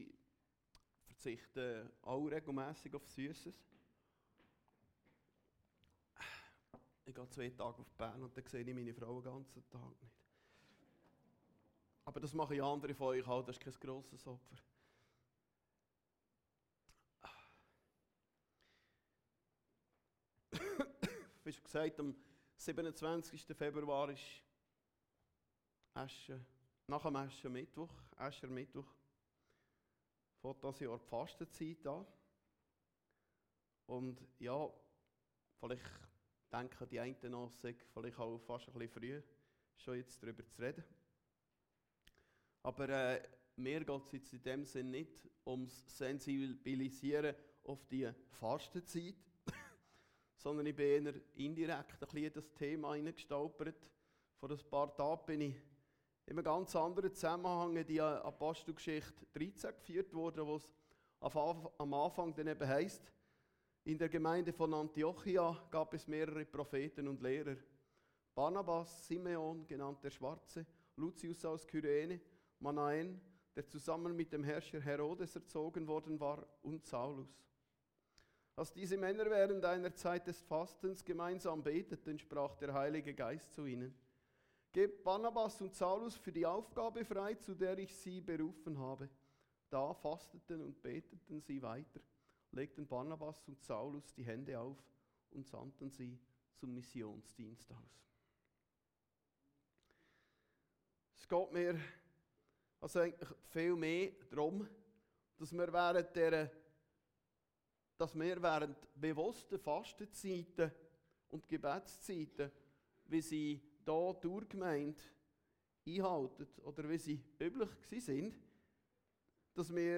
Predigten Heilsarmee Aargau Süd – Fasten und Beten